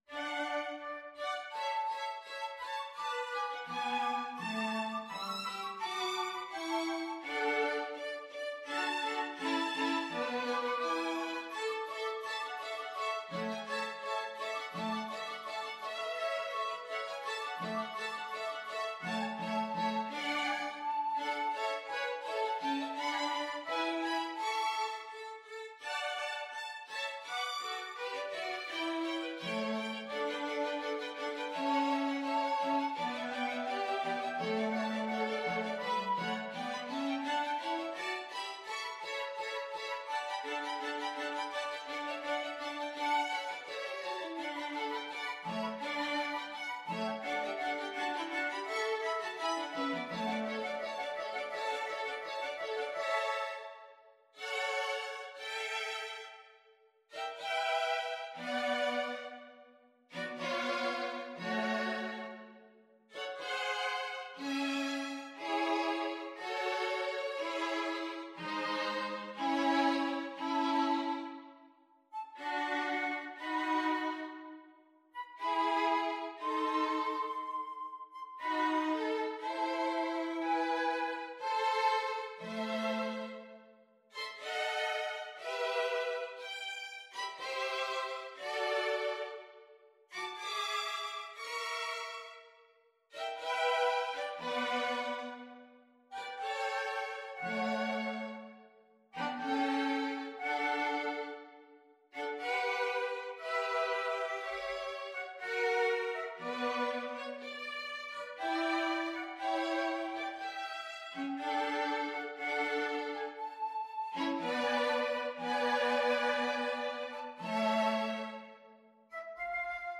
FluteViolin 1Violin 2Bass
Flute and Ensemble  (View more Intermediate Flute and Ensemble Music)
Classical (View more Classical Flute and Ensemble Music)